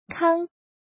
怎么读
kāng